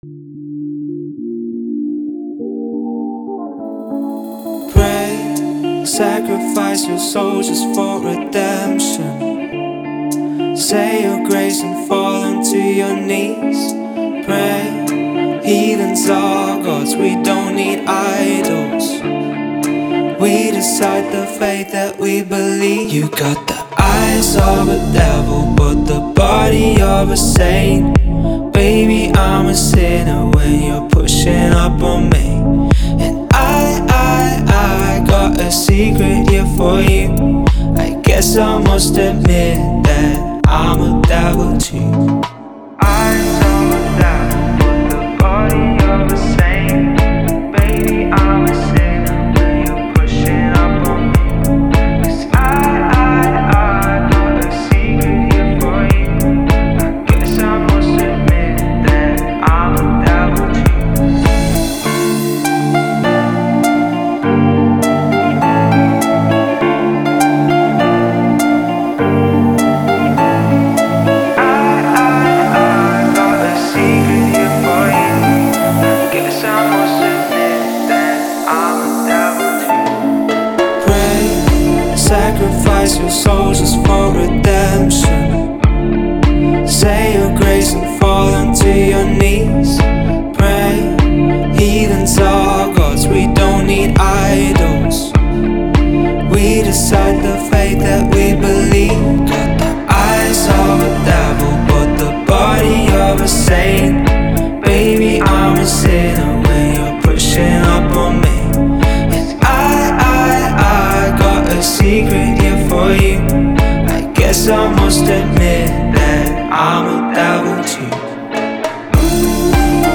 выполненная в жанре прогрессивного хауса.